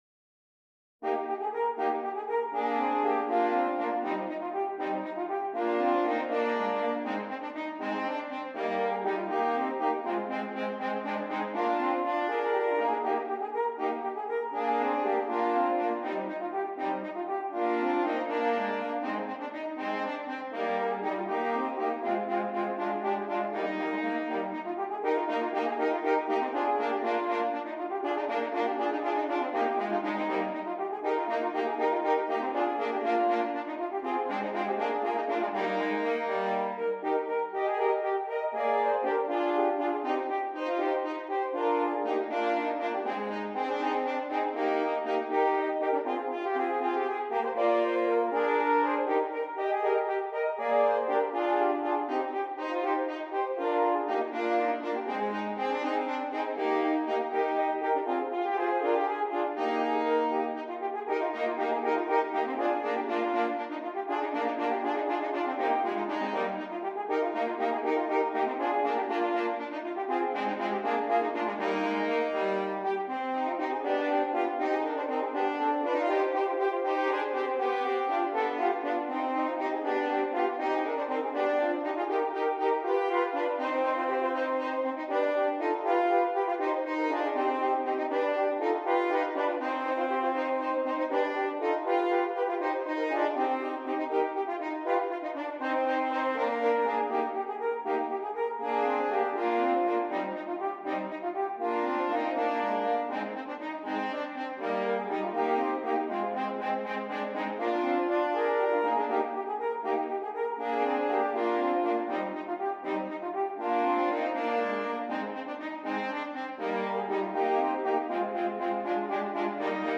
3 F Horns